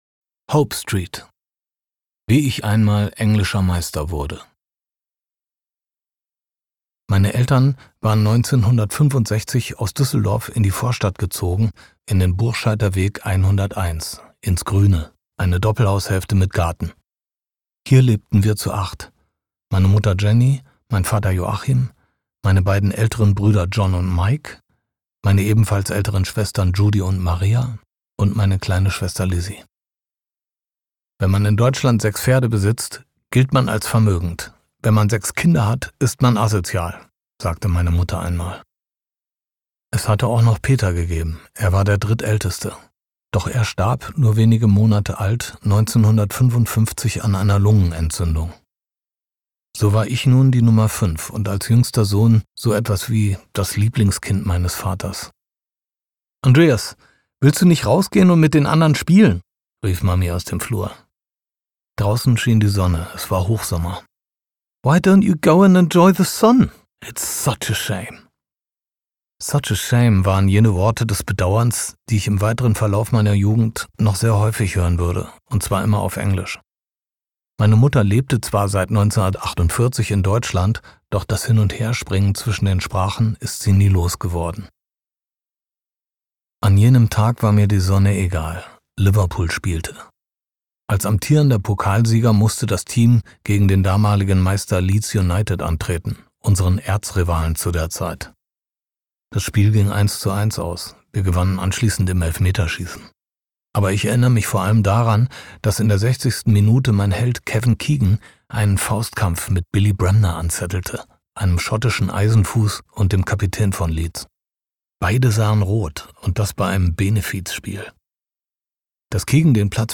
Campino (Sprecher)
Ungekürzte Autorenlesung mit 6 exklusiven Songs! Gesungen und gespielt von Campino und Kuddel.